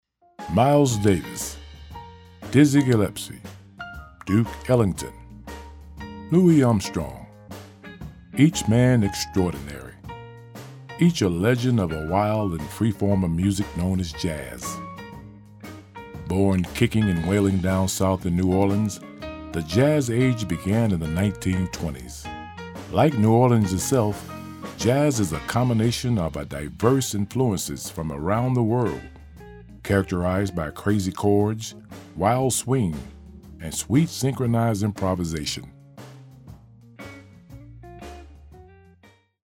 Legends of Jazz Smooth tone, narration
African American
Middle Aged
Voice is a deeper smooth tone.
Legends of Jazz with music.mp3